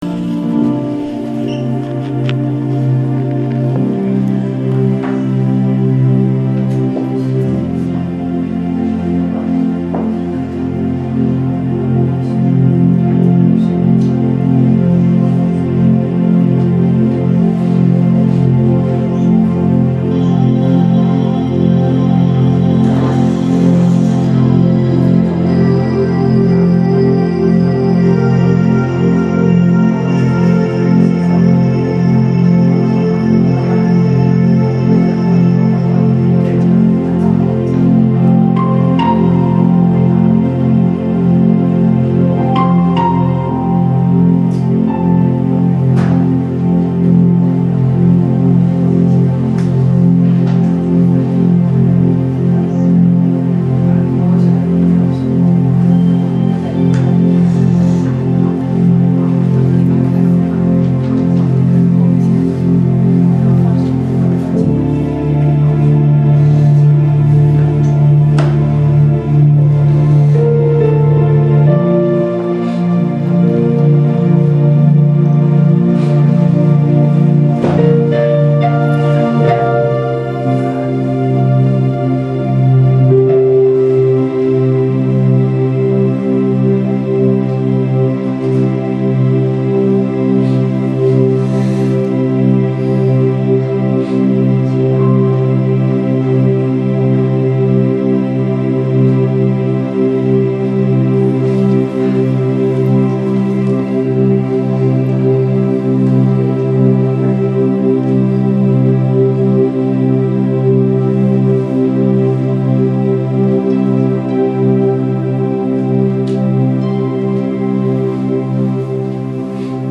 主日恩膏聚会录音